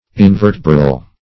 \In*ver"te*bral\